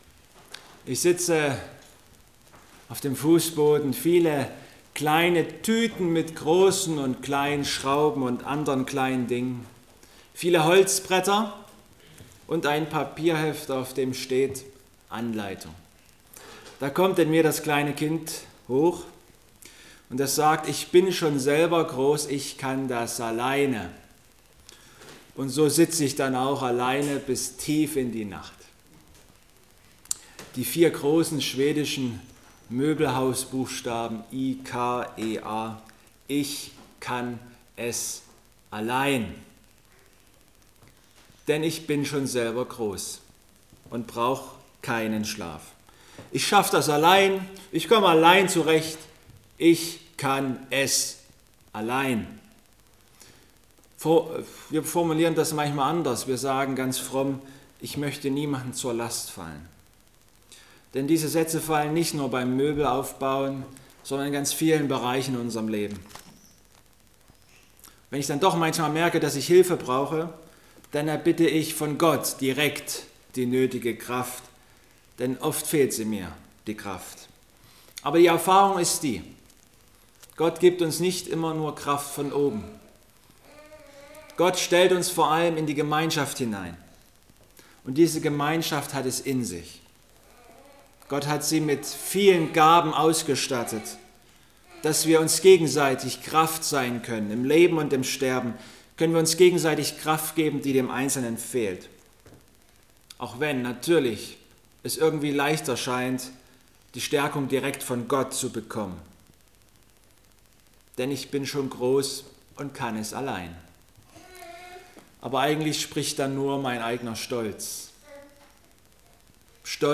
Pet 4,7-11 Gottesdienstart: Abendmahlsgottesdienst EIn älterer Herr fährt mit seinem großen Auto.